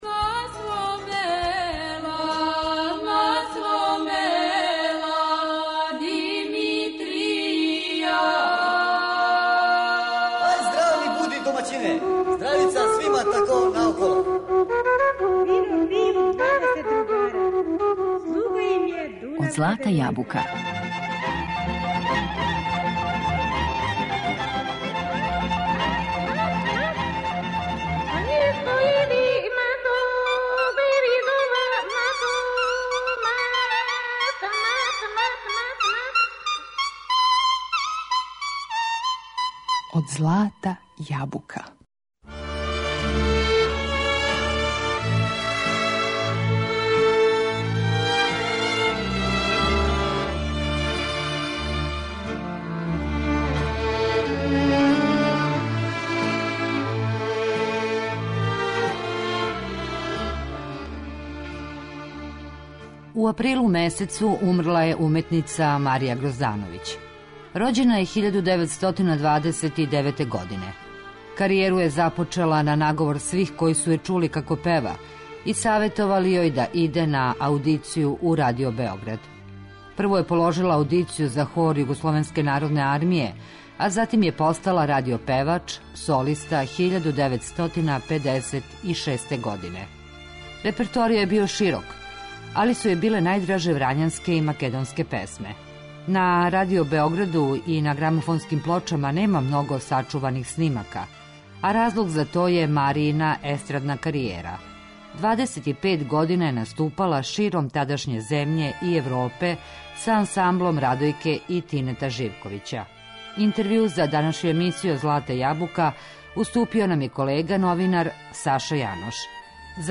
Оставила је у Тонском архиву Радио Београда забележене трајне снимке врхунске вредности, са скоро свим оркестрима.